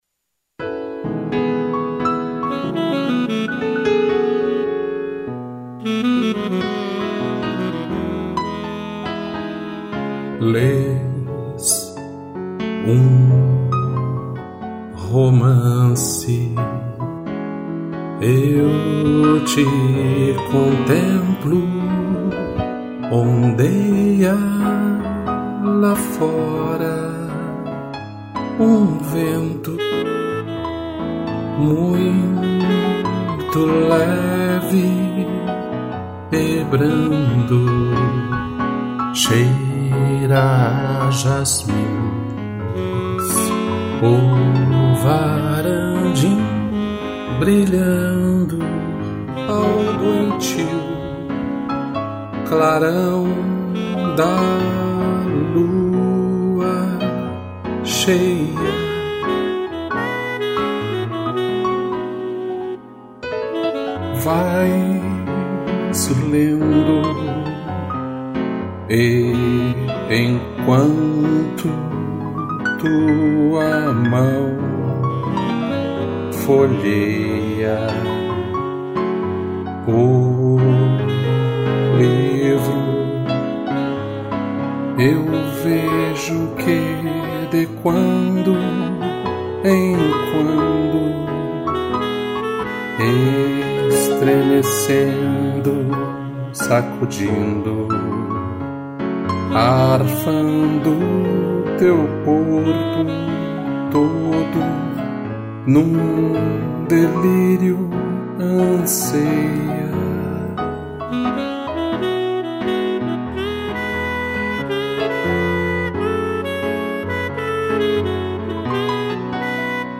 voz
2 pianos e sax